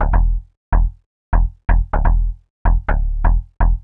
cch_bass_funky_125_D.wav